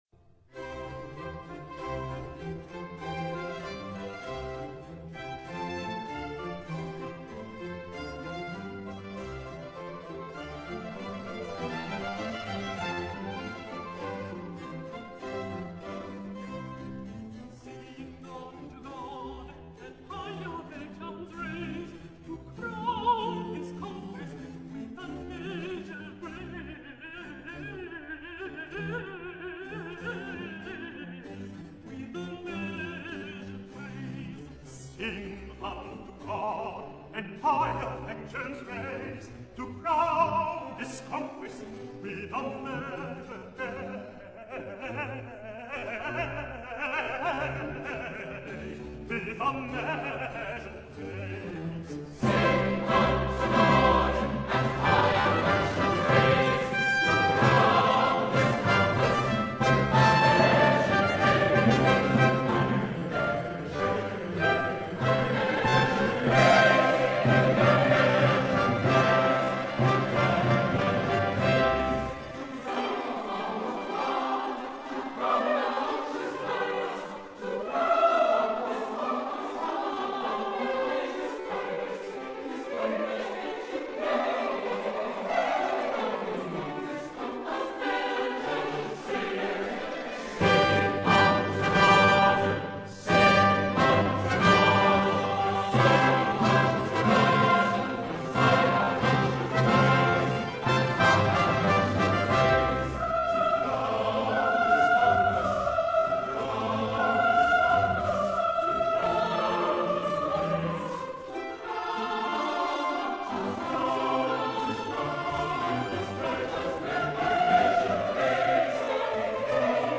Àudio MP3 més lent: